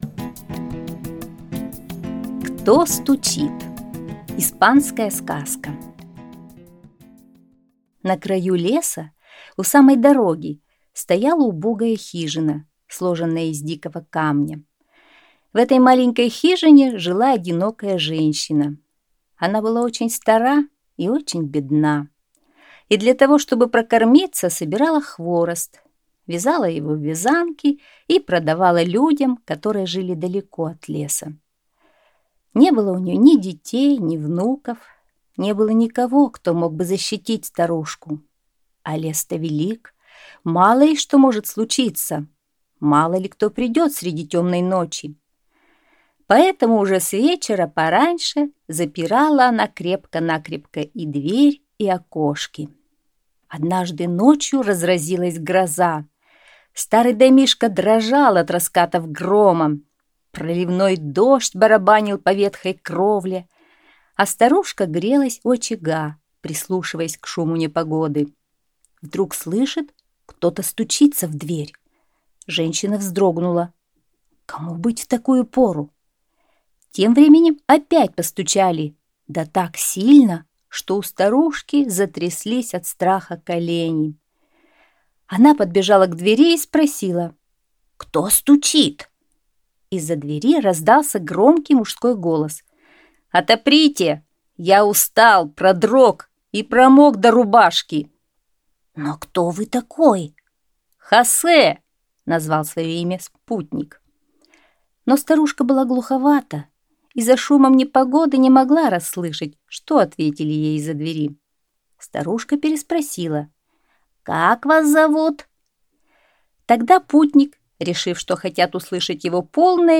Аудиосказка «Кто стучит?»